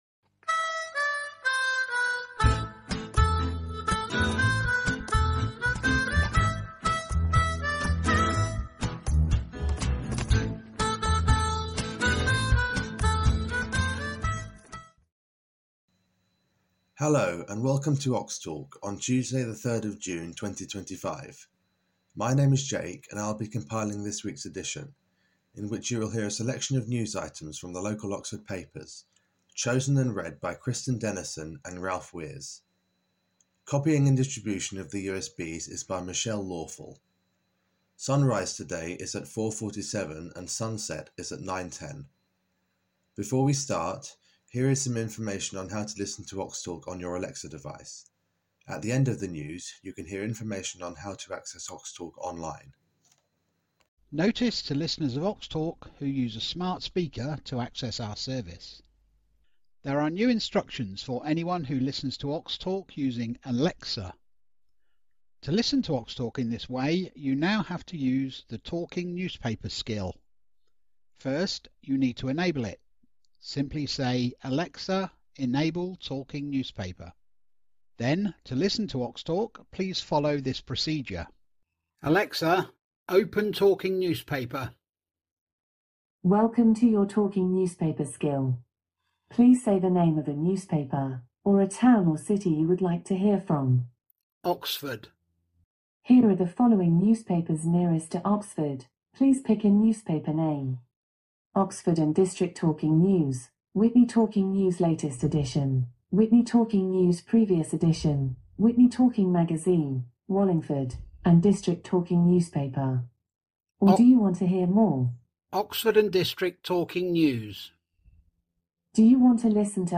Oxtalk Talking newspapers for blind and visually impaired people in Oxford & district